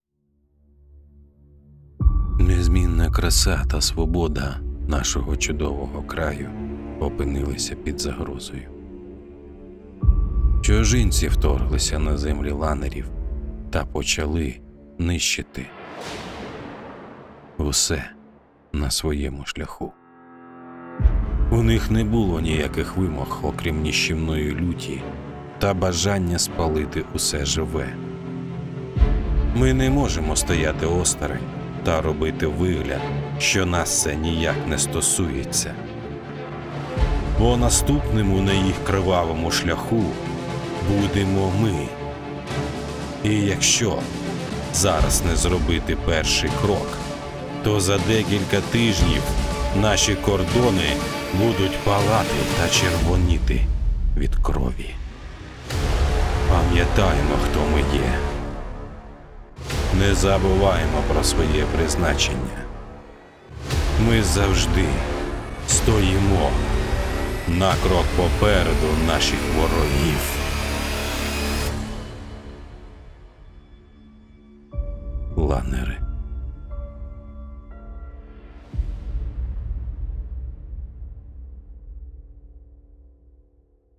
Трейлер "Ланери"